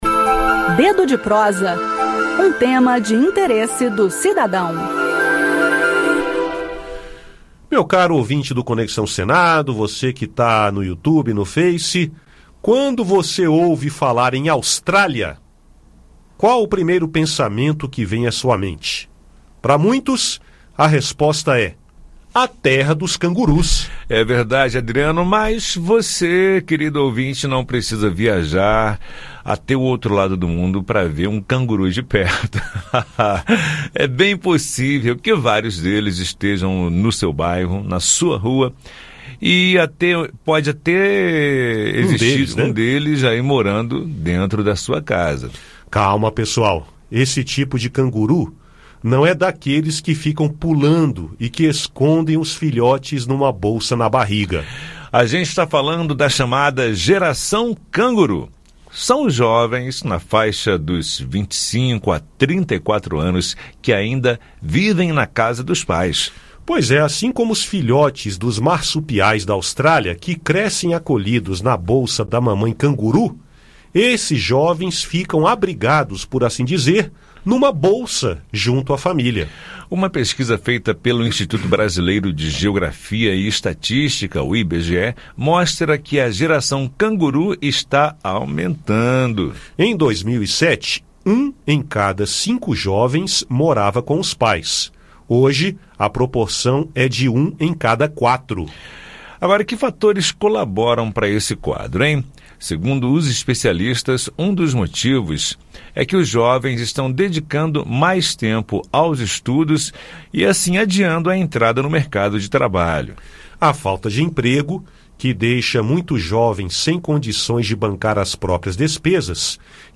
O bate-papo do Dedo de Prosa traz esse assunto.